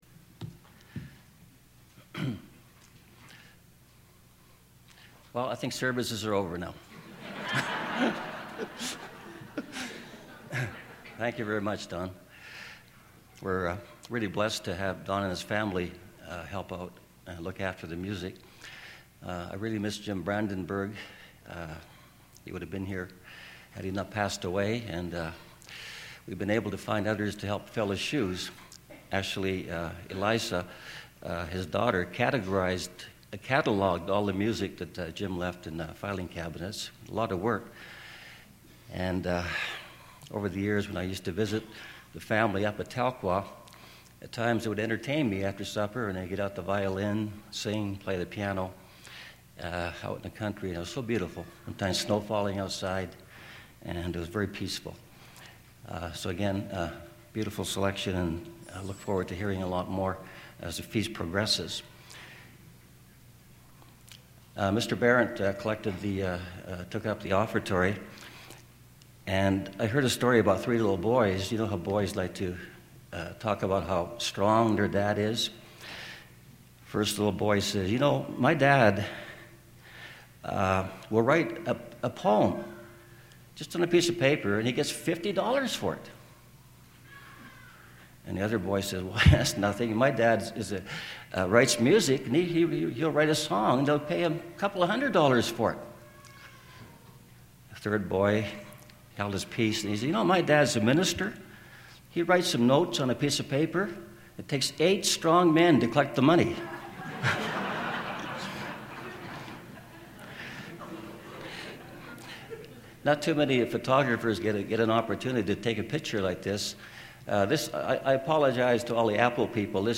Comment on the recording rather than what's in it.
This sermon was given at the Canmore, Alberta 2011 Feast site.